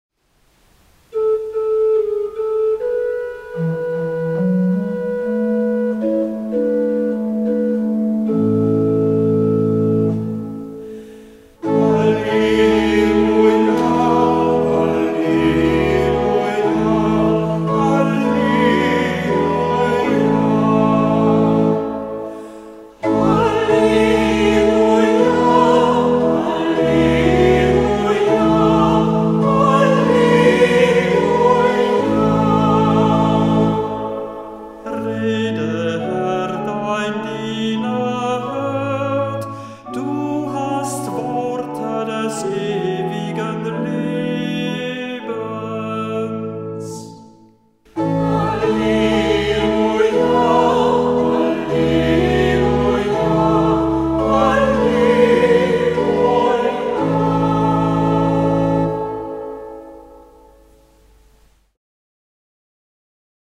Hörbeispiele aus dem Halleluja-Büchlein